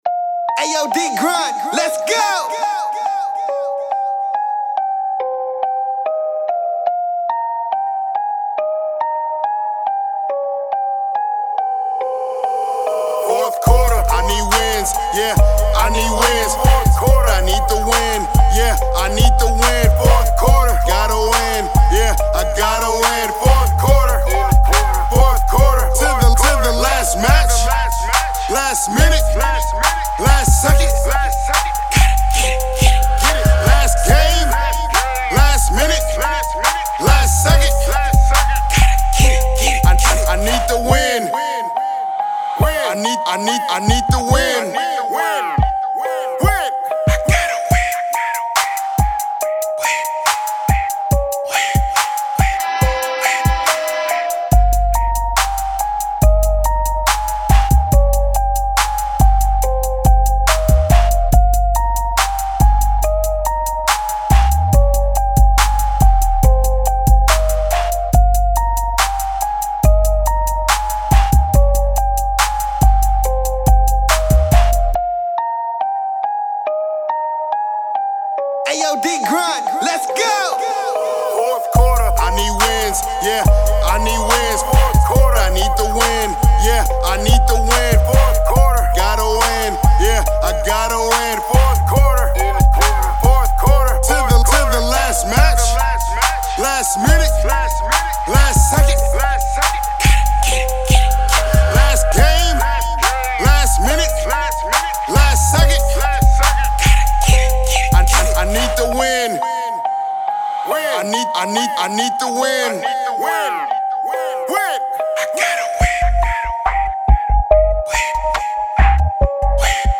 Hiphop
Verses open for remix.